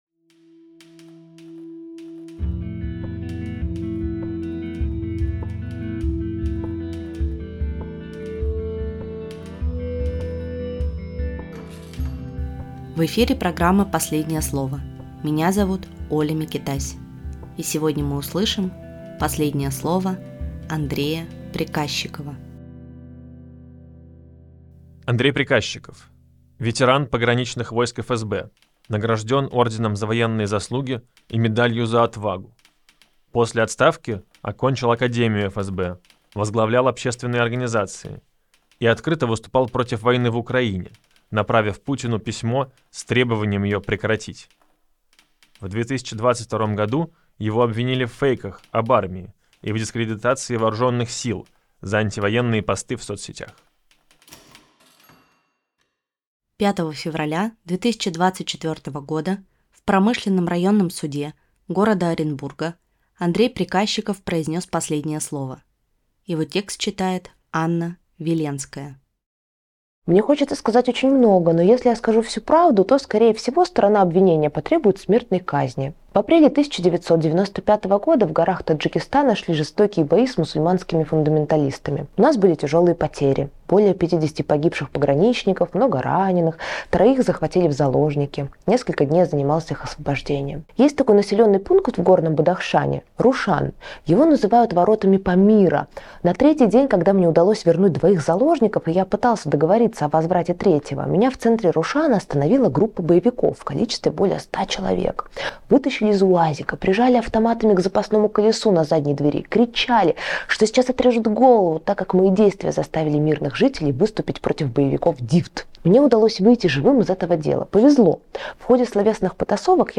В эфире программа «Последнее слово».